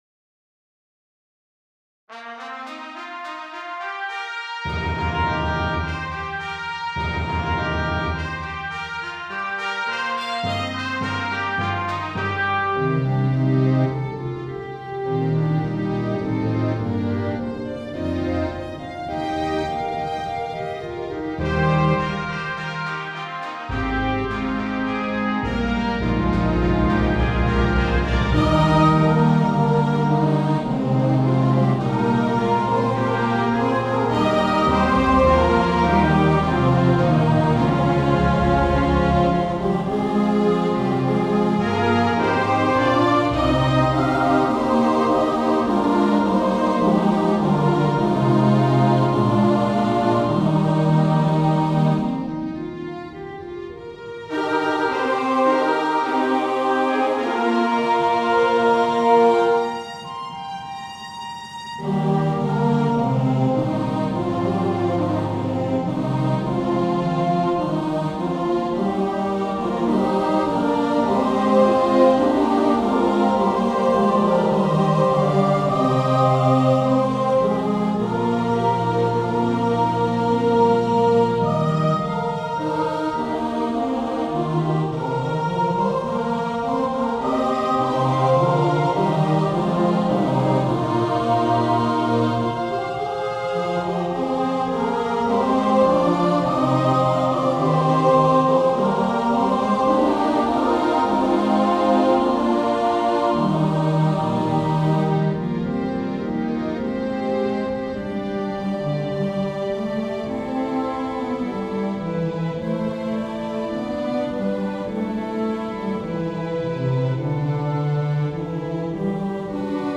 • Music Type: Choral
• Voicing: SATB
• Accompaniment: Organ, Timpani, Trombone, Trumpet
A majestic and exciting festival anthem